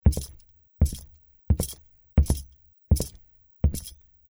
带有金属链的皮靴在木板地上行走-低频YS070525.mp3
通用动作/01人物/01移动状态/木质地面/带有金属链的皮靴在木板地上行走-低频YS070525.mp3
• 声道 立體聲 (2ch)